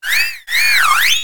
Cri de Tournicoton dans Pokémon HOME.